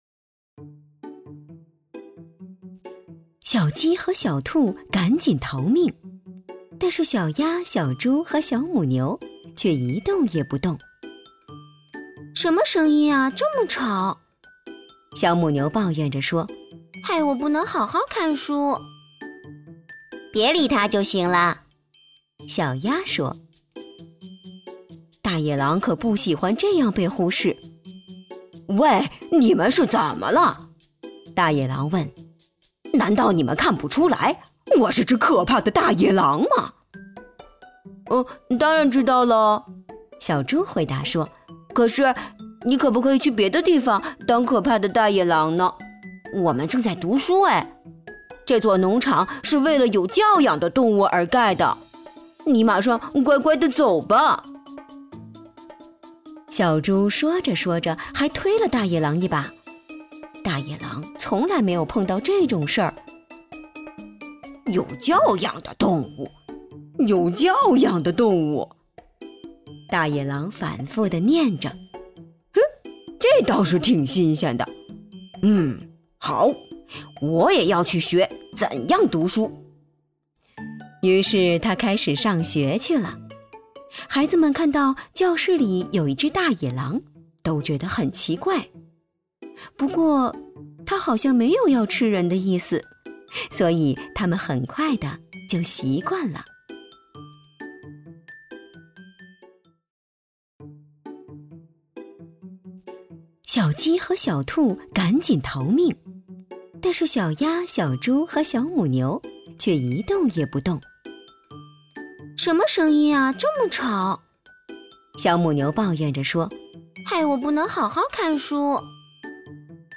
• 女S129 国语 女声 儿童绘本-多角色-【温婉旁白+娇气小母牛+淘气小鸭+凶恶野狼+憨笨小猪】童话故事 亲切甜美|素人